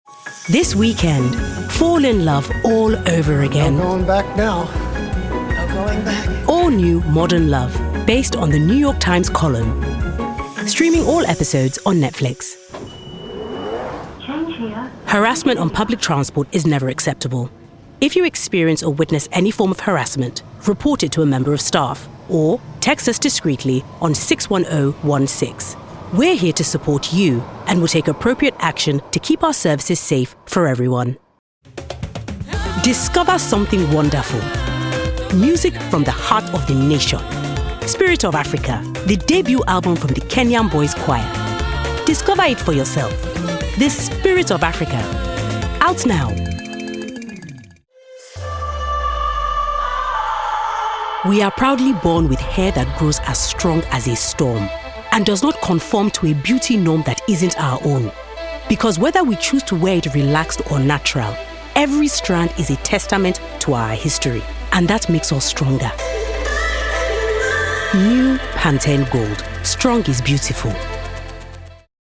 Female. African/Black British.
Commercials